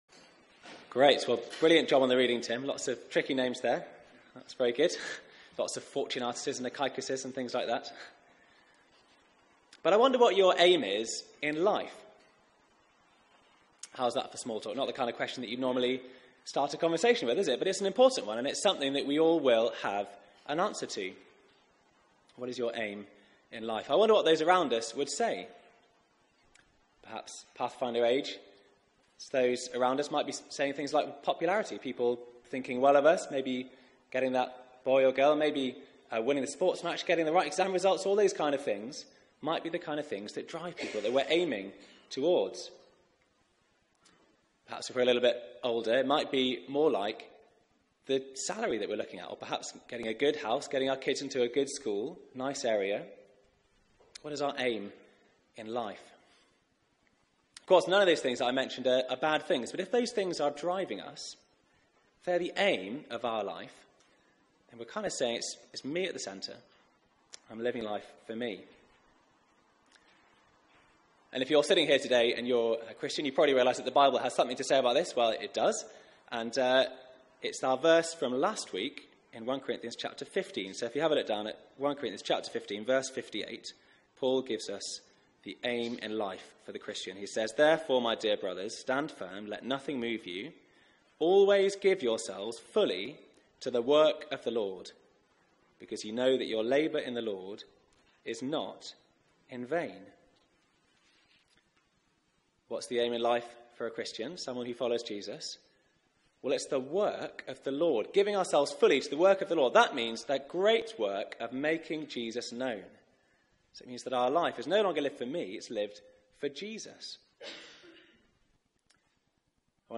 Media for 4pm Service on Sun 14th May 2017 16:00 Speaker
Sermon